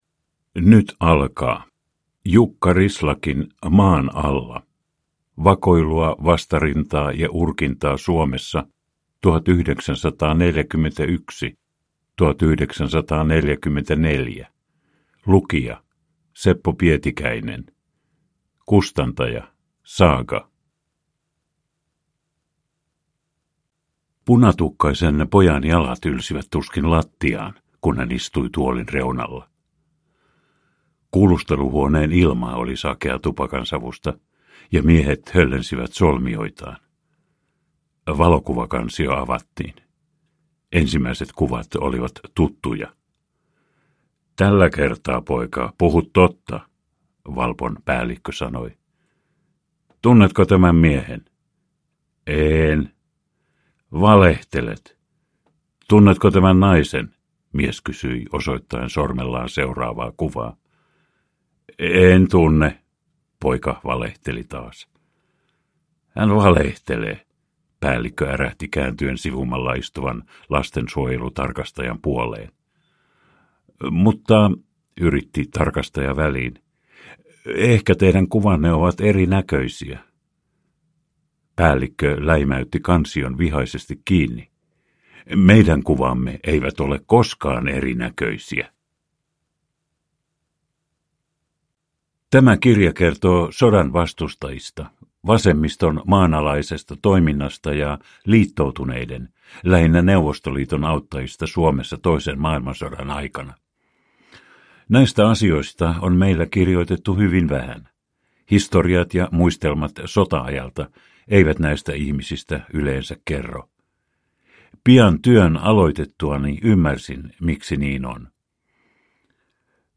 Maan alla: Vakoilua, vastarintaa ja urkintaa Suomessa 1941-1944 (ljudbok) av Jukka Rislakki